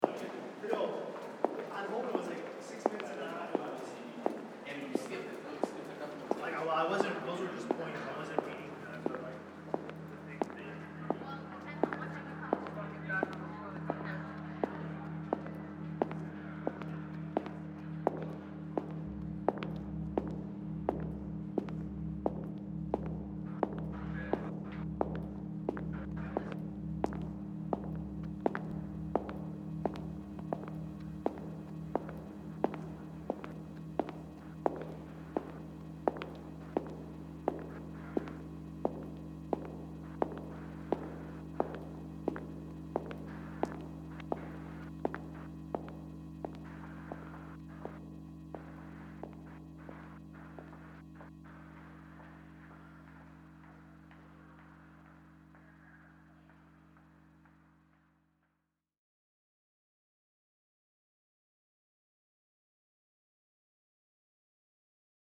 Compositional excerpts were created using a mixture of recognizable, real-world field recordings, processed recordings and synthetic pitched materials. Several excerpts exhibit phonographic approaches, while others involve sound-image transformations (i.e. sonic transmutation between two recognizable sounds) or interplay between synthetic pitched materials and concrète materials. The excerpts contained some repeating sounds — for instance, the sound of footsteps — while other sounds appeared only once.